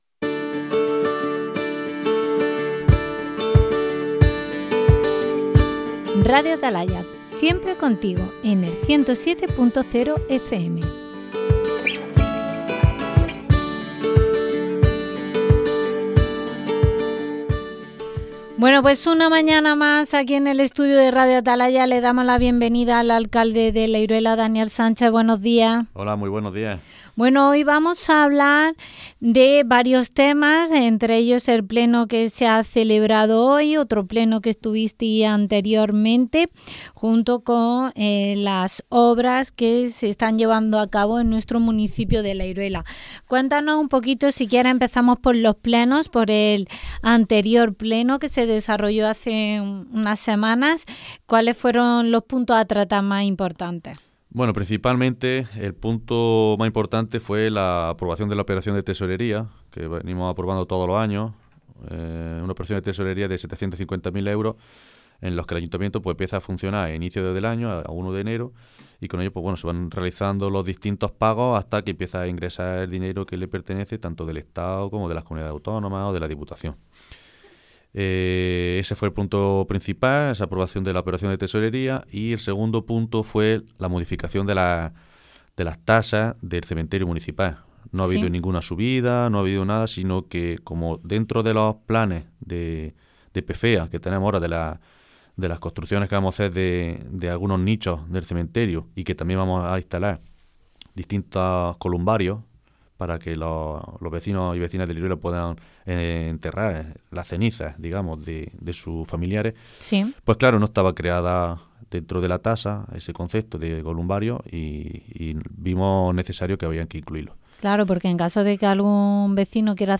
Entrevistas - RADIO ATALAYA LA IRUELA
Alcalde-La-Iruela-Daniel-Sanchez-Ultimos-Plenos-Obras-y-Nuevo-Sendero.mp3